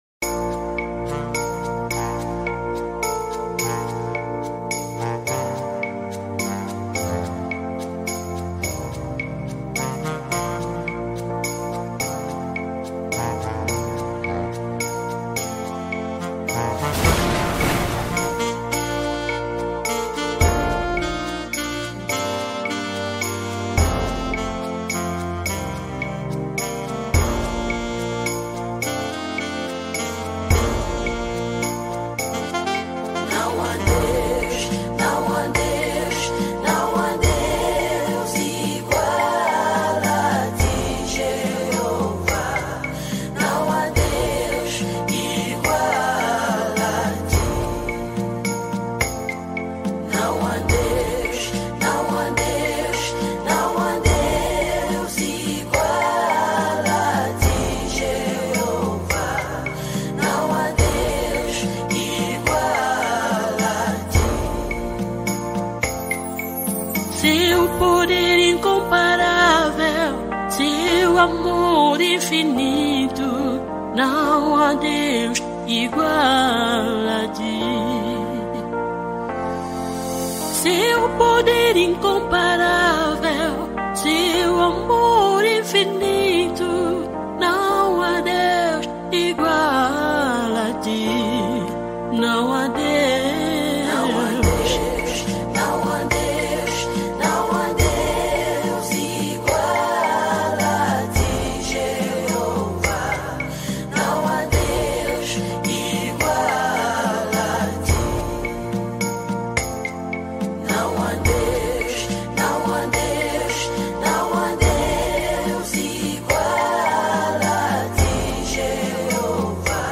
Gospel 2012